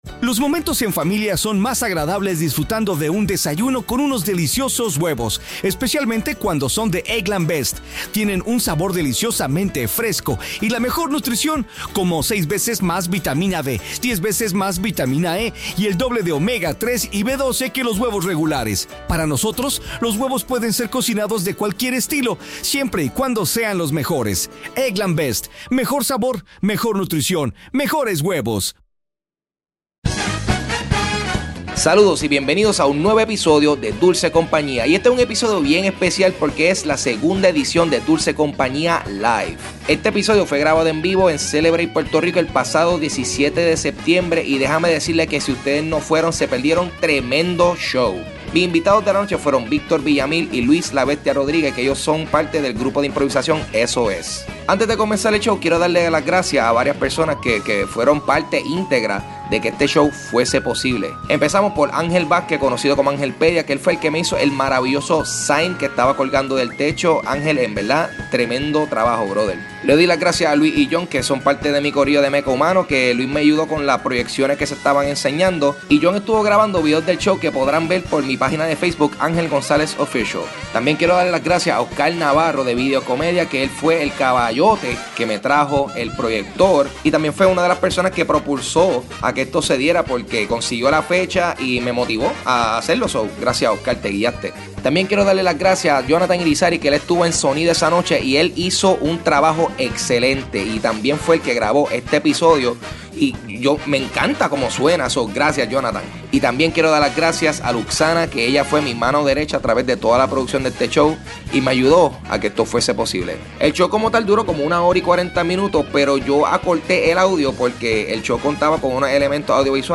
Grabado el 17 de septiembre de 2015 en Celebrate.